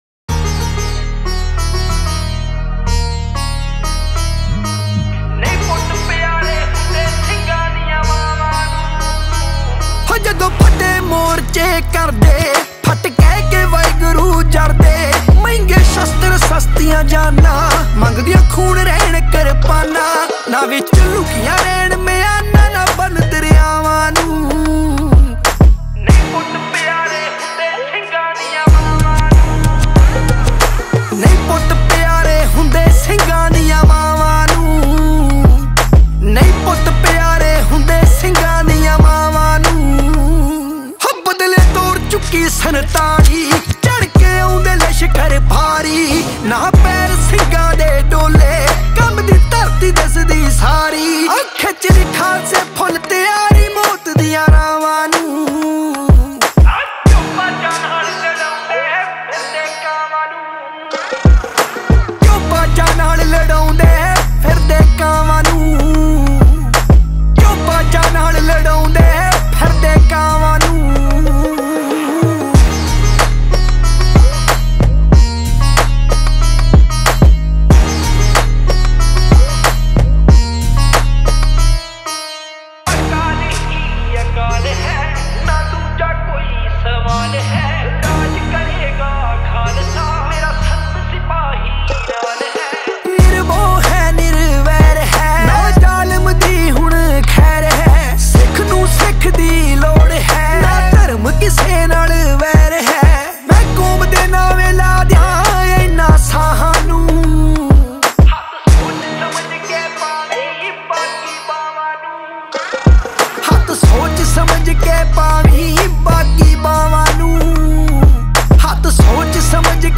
Punjabi Single Track